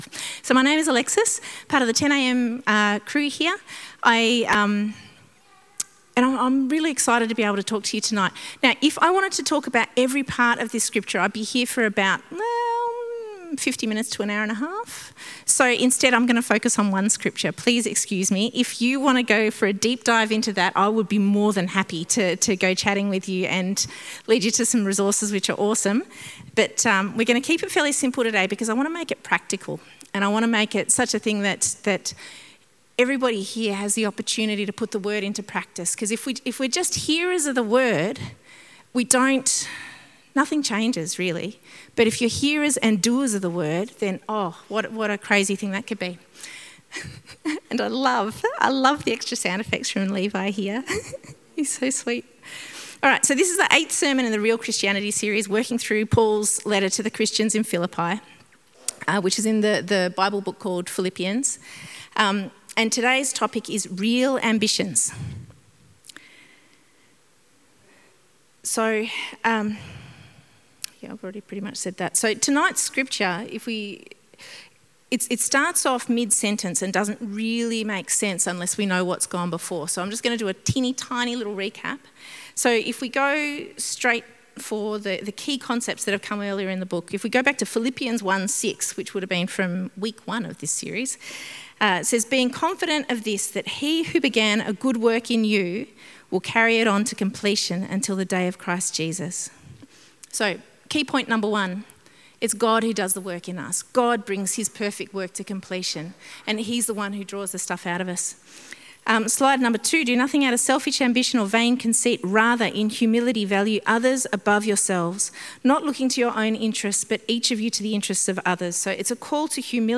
Sermons | St Hilary's Anglican Church